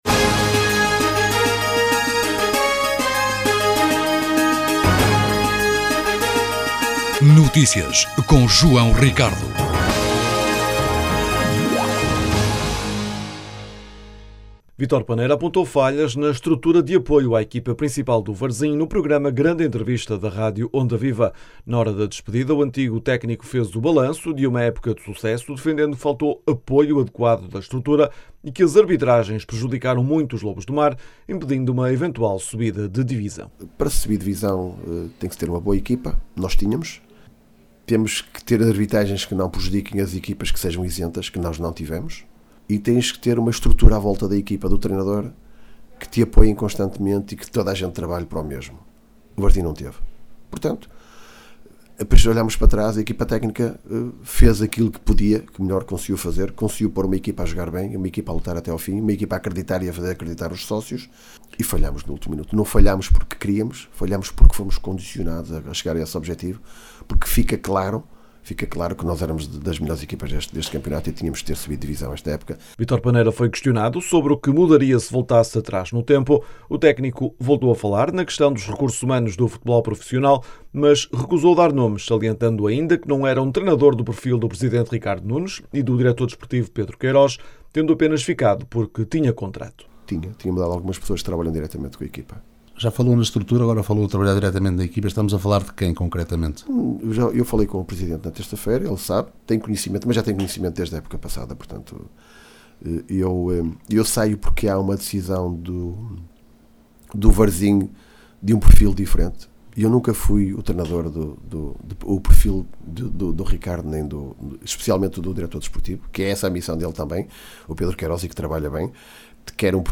Vítor Paneira apontou falhas no apoio à equipa principal do Varzim no programa Grande Entrevista da Rádio Onda Viva. Na hora da despedida, o antigo treinador fez o balanço de uma época de sucesso, defendendo que faltou um apoio adequado da estrutura e que as arbitragens prejudicaram muito os lobos do mar, impedindo uma eventual subida de divisão.
Num discurso corrosivo, Paneira admitiu que faltou compromisso, rigor, profissionalismo e dedicação para que fosse possível ir mais longe.